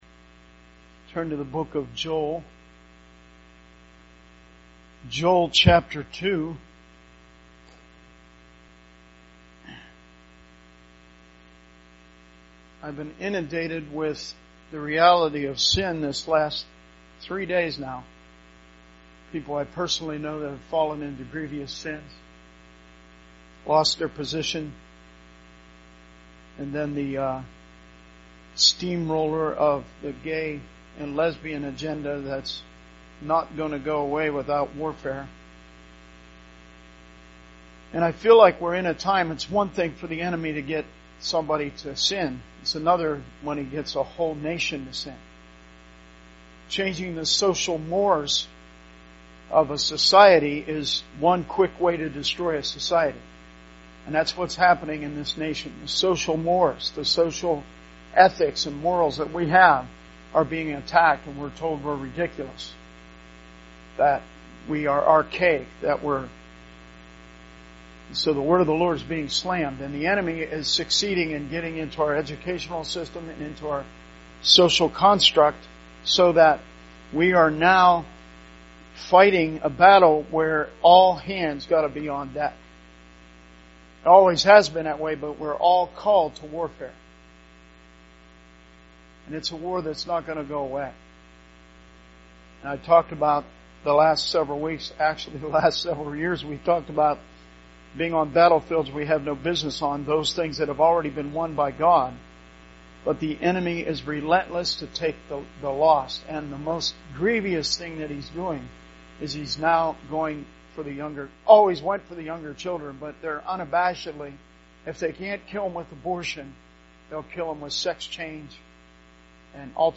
Sermon messages available online.